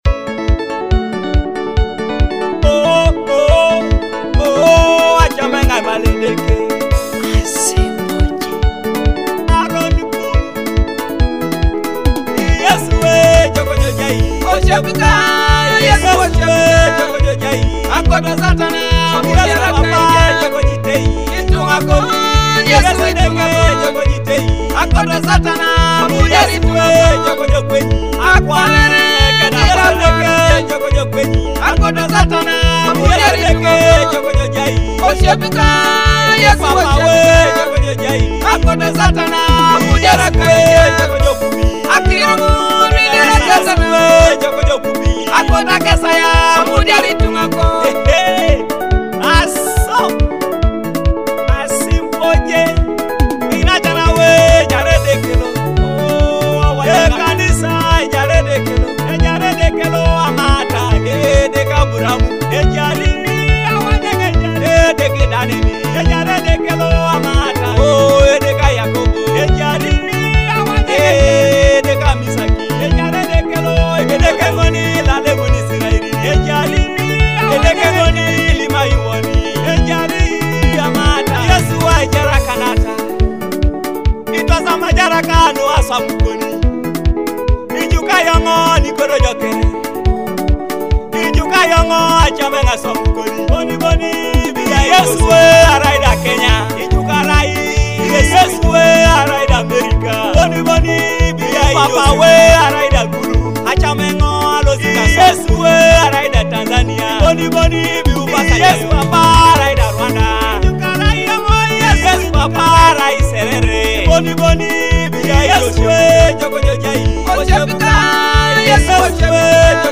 a heartfelt gospel track that celebrates redemption